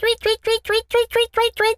bird_tweety_tweet_01.wav